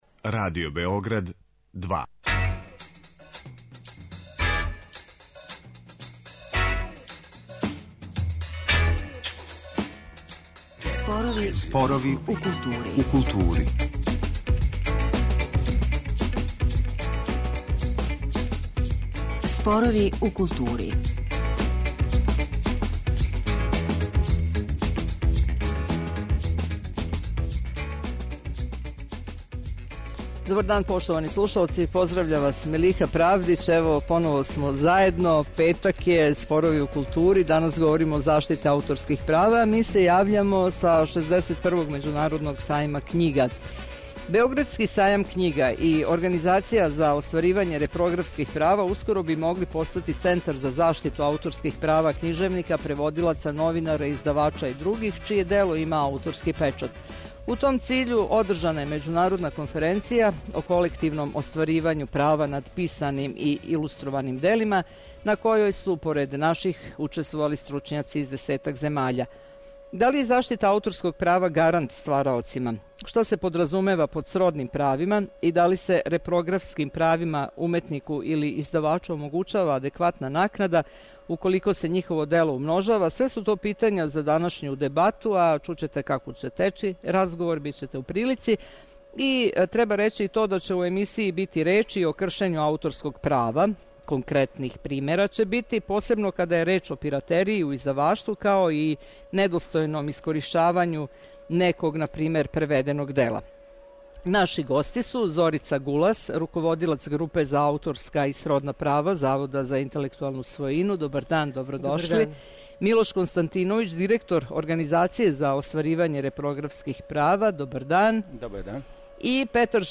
Да ли је заштита ауторског права гарант ствараоцима, шта се подразумева под сродним правима и да ли се репрографским правима уметнику или издавачу омогућава адекватна накнада, уколико се њихово дело умножава - све су то питања за данашњу дебату.